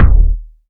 KICK.110.NEPT.wav